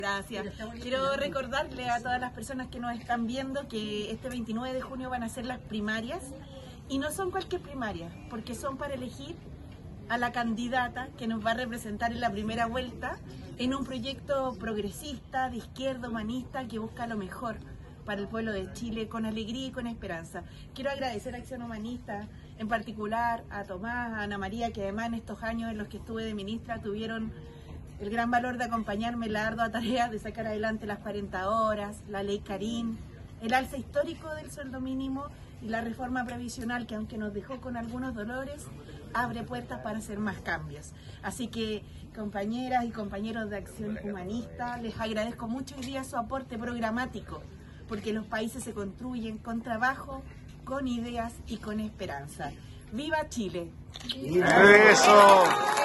En la Casa Museo Michoacán de los Guindos, en la comuna de La Reina, la candidata presidencial Jeannette Jara, recibió de manos del Equipo de Coordinación Nacional del partido Acción Humanista su propuesta para nutrir el futuro programa de Gobierno, de cara a las primarias presidenciales de Unidad por Chile, el 29 de junio.